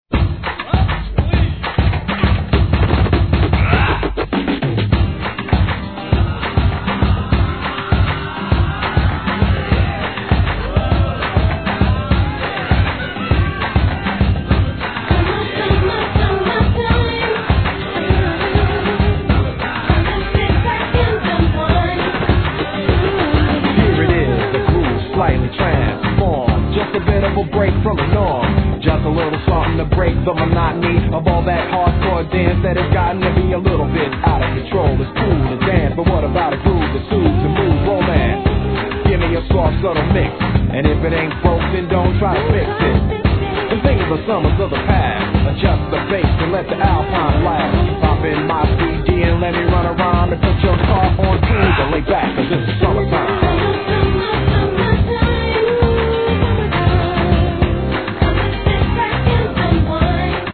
HIP HOP/R&B
REGGAEのスパイスを加えHIGHテンションREMIX!!